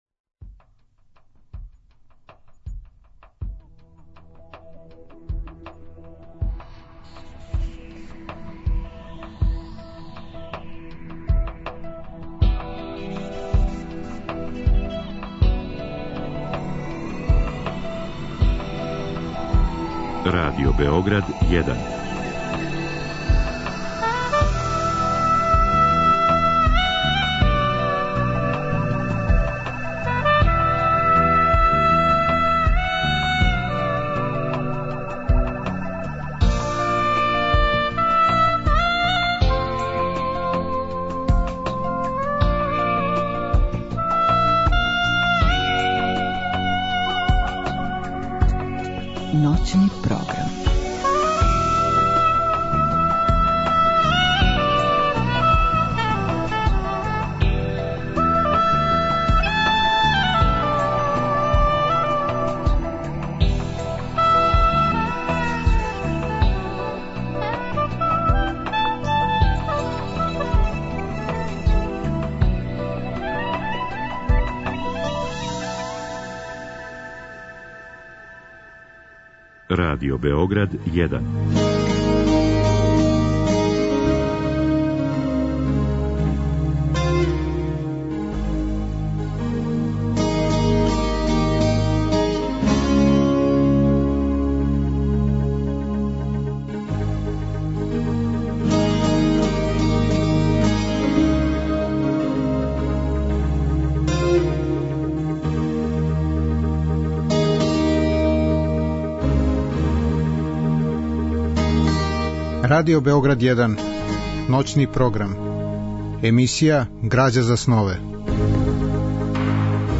Разговор и добра музика требало би да кроз ову емисију и сами постану грађа за снове.
слушаћемо одабране делове из радио-драме Данила Киша „Прича од које се црвени"